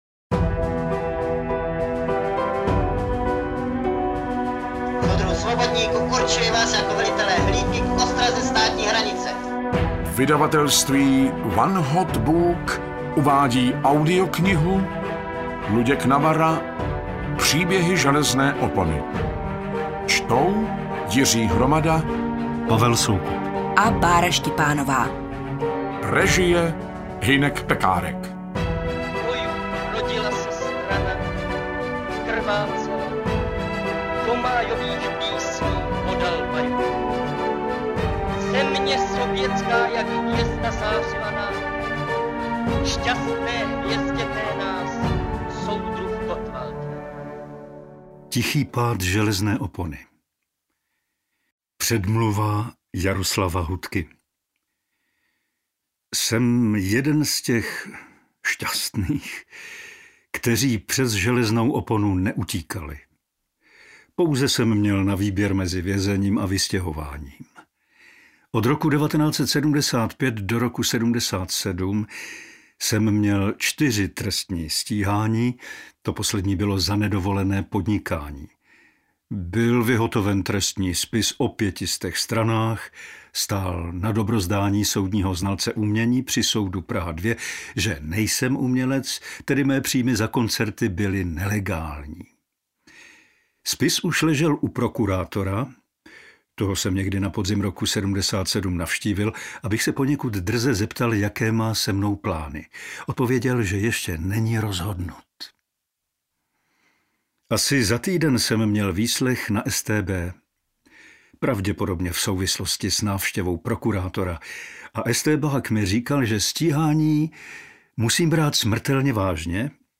V podobě audioknihy namluveno výborně skvělými herci.
AudioKniha ke stažení, 40 x mp3, délka 11 hod. 45 min., velikost 628,0 MB, česky